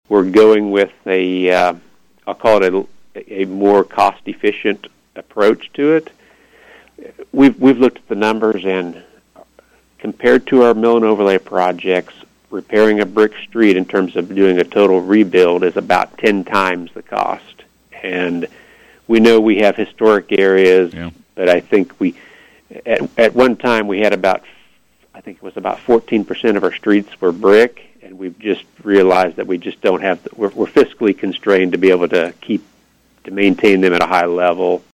a guest on KVOE’s Morning Show recently